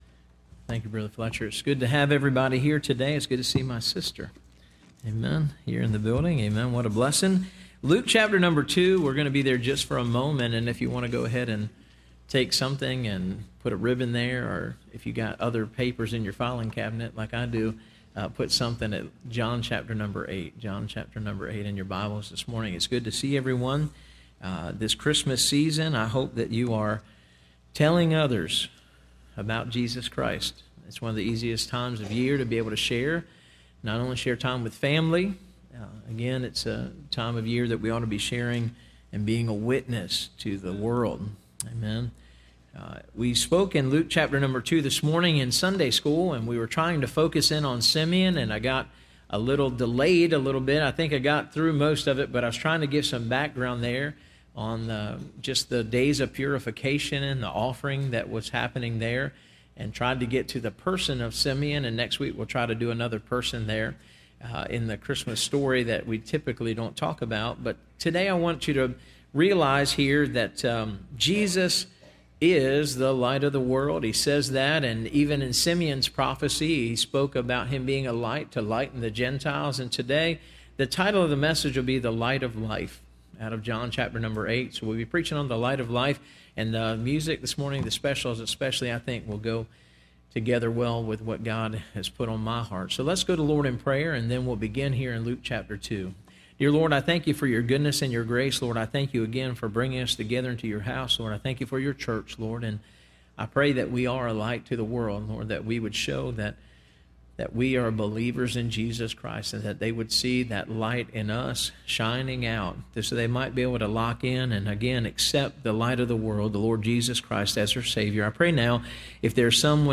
Listen to Message
Service Type: Sunday Morning